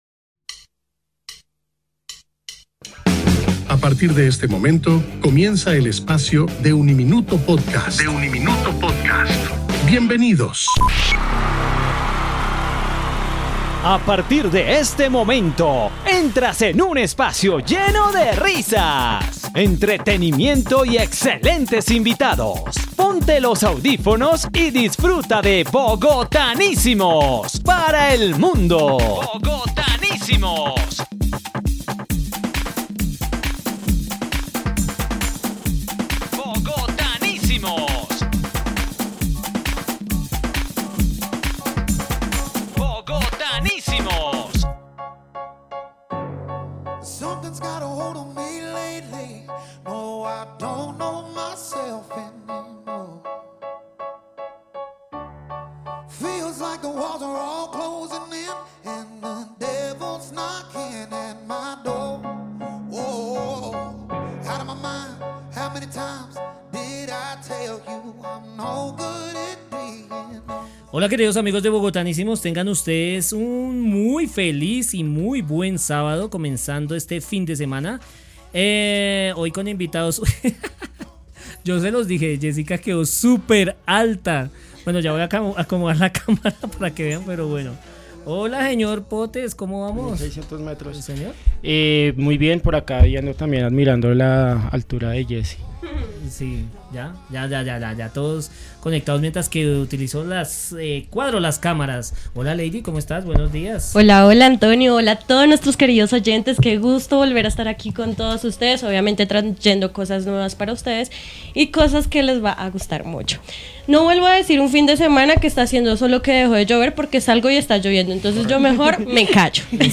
Durante la conversación, también habló de sus colaboraciones con músicos de Manizales y Valledupar, experiencias que han nutrido su estilo y visión artística. El programa cerró con su más reciente sencillo, una muestra de su evolución sonora y su compromiso con el arte hecho desde el alma.